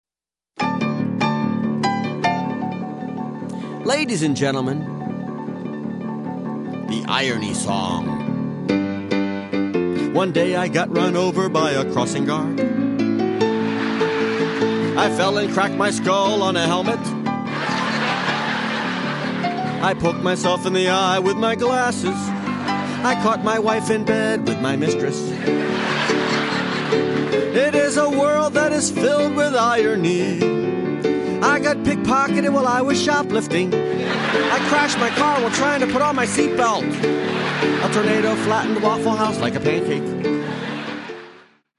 --comedy music and standup